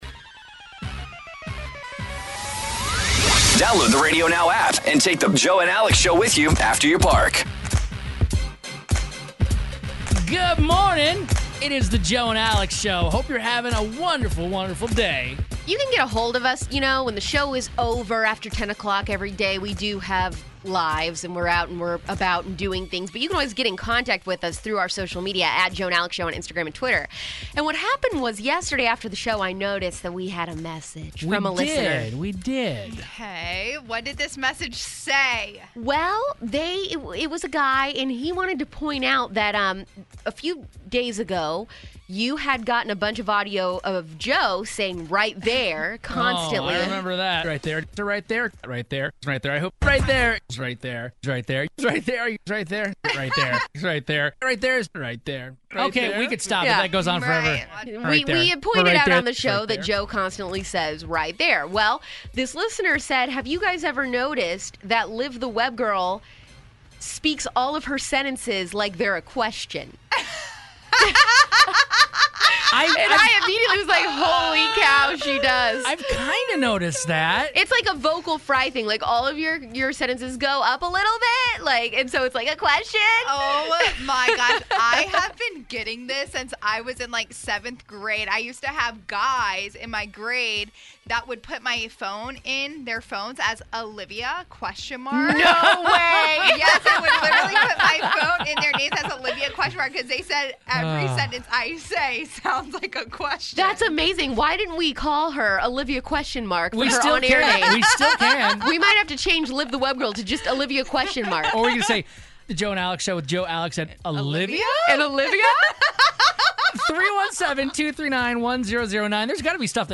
Listeners like to point out things we ALWAYS say on air, or bad habits we have while on air, so we opened it up for them to roast us live on air about it.